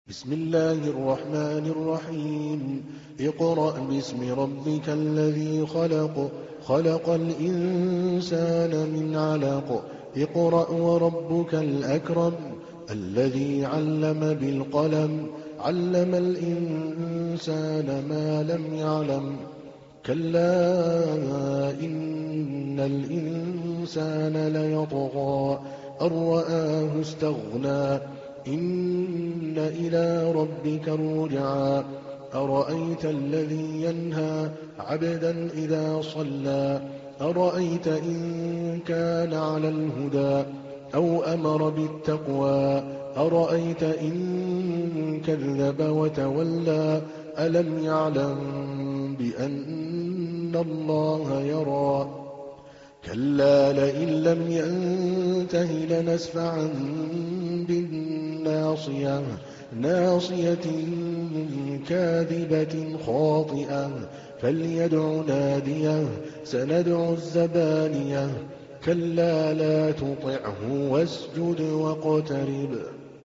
تحميل : 96. سورة العلق / القارئ عادل الكلباني / القرآن الكريم / موقع يا حسين